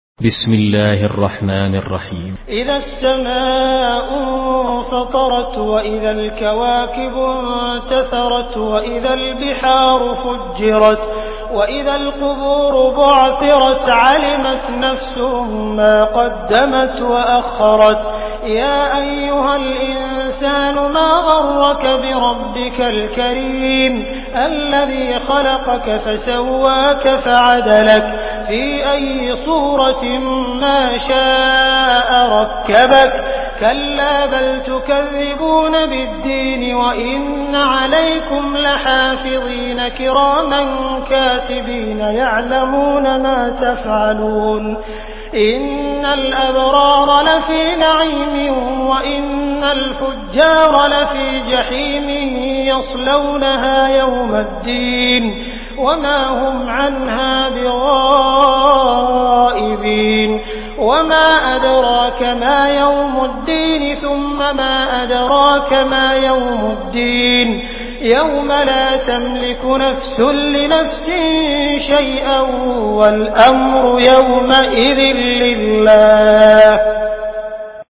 Surah Al-Infitar MP3 by Abdul Rahman Al Sudais in Hafs An Asim narration.
Murattal Hafs An Asim